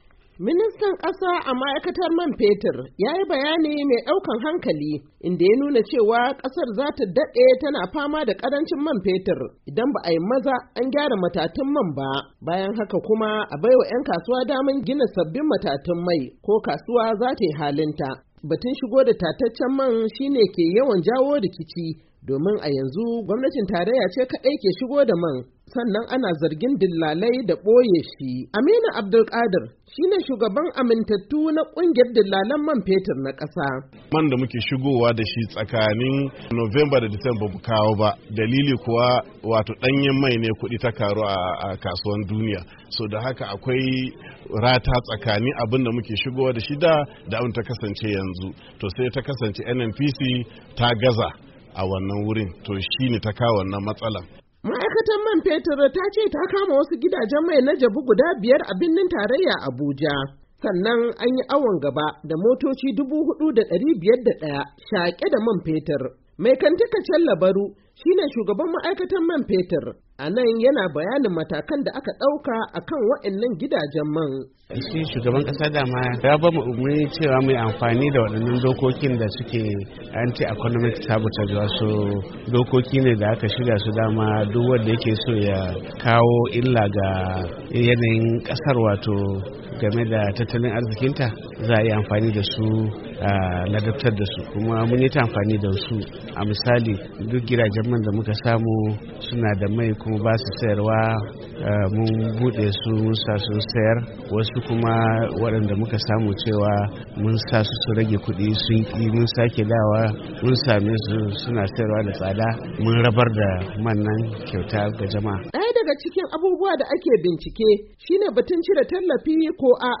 da karin bayani daga Abuja.